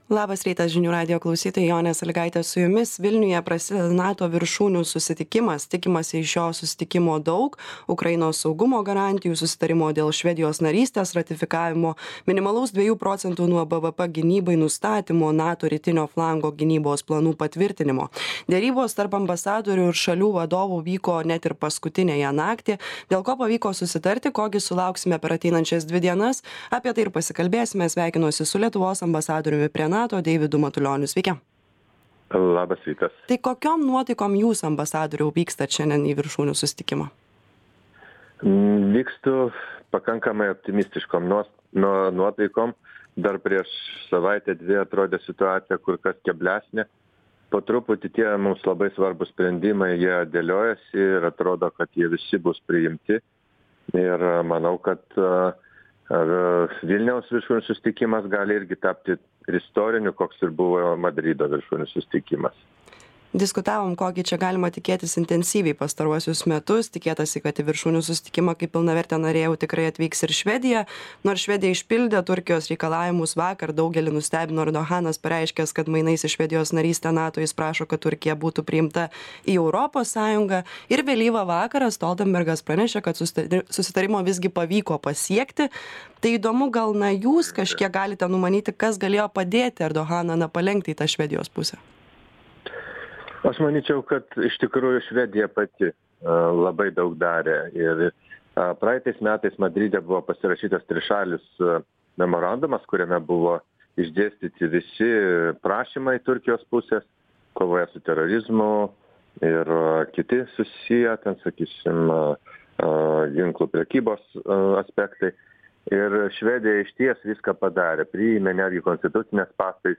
Pokalbis su Lietuvos ambasadoriumi prie NATO Deividu Matulioniu.